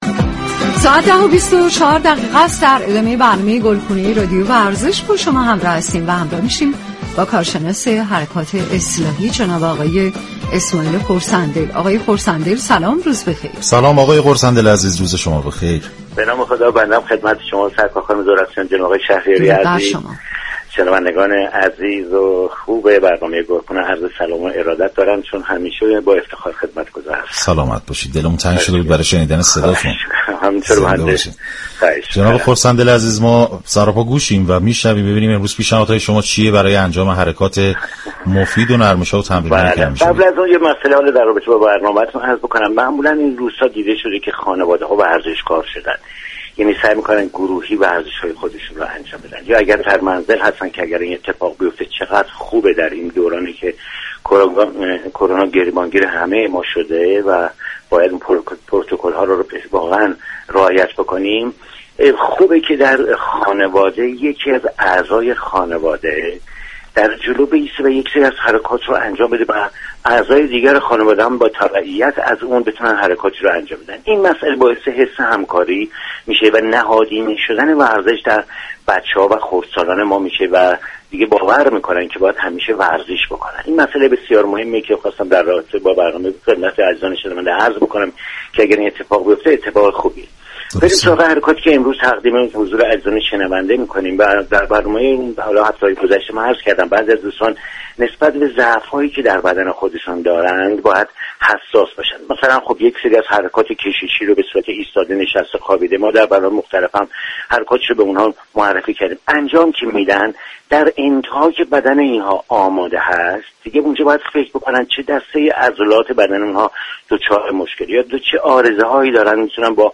این برنامه با محوریت ترویج سبك زندگی اسلامی ایرانی با هدف ایجاد و تقویت انگیزه برای بهبود وضعیت سواد حركتی در نهاد خانواده ساعت 10 هر روز به مدت 60 دقیقه از شبكه رادیویی ورزش تقدیم شنوندگان می شود.